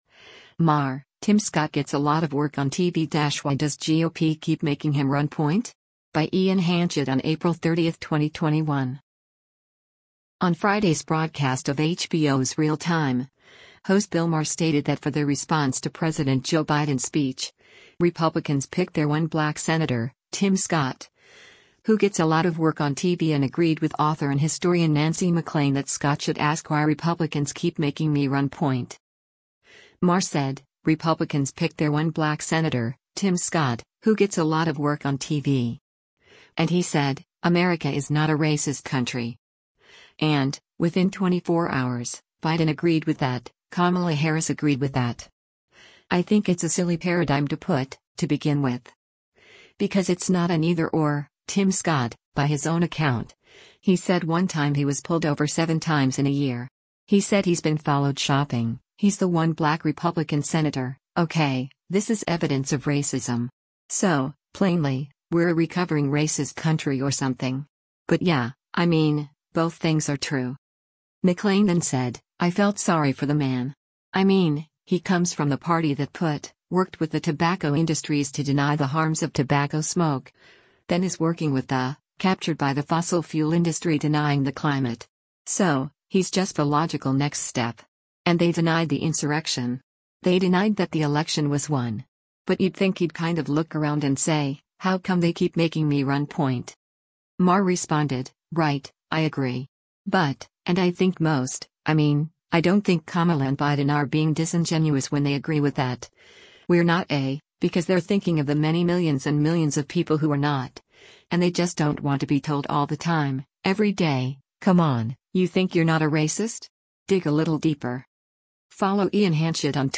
On Friday’s broadcast of HBO’s “Real Time,” host Bill Maher stated that for their response to President Joe Biden’s speech, “Republicans picked their one black senator, Tim Scott, who gets a lot of work on TV” and agreed with author and historian Nancy MacLean that Scott should ask why Republicans keep “making me run point?”